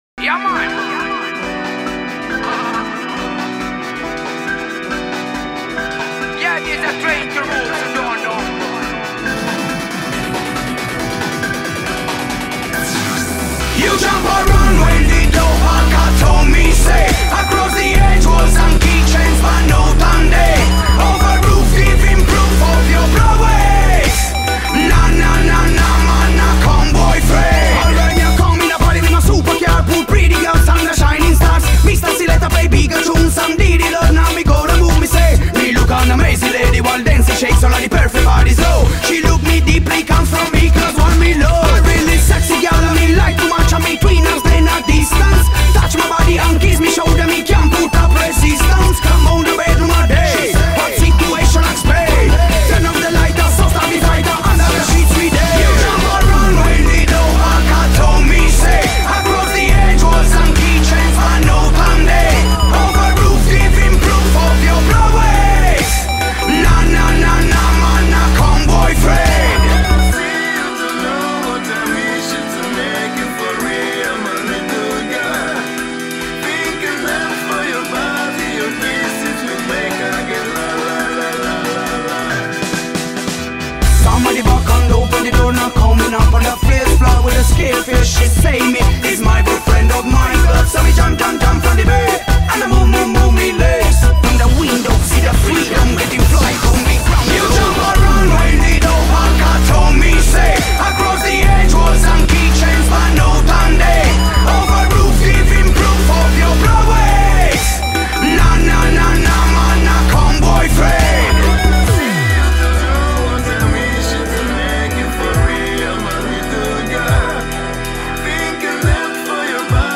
ARTISTA A LA VISTA | INTERVISTA